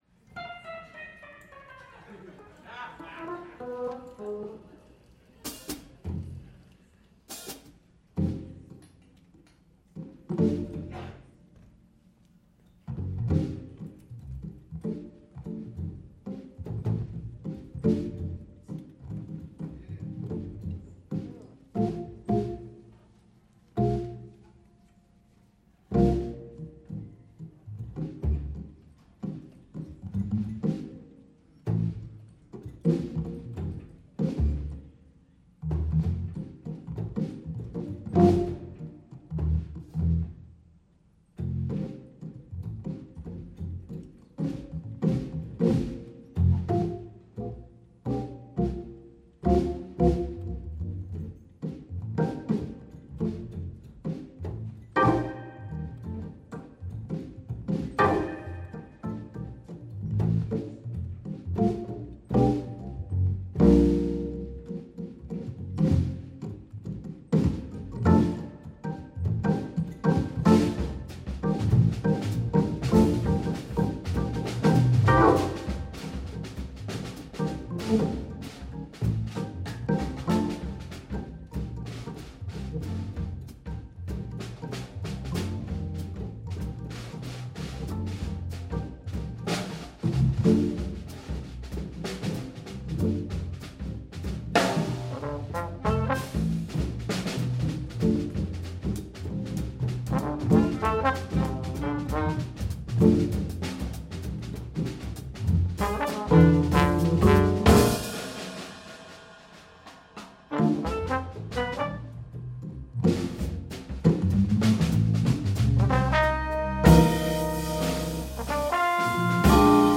Drums
Organ
Trombone
Clarinet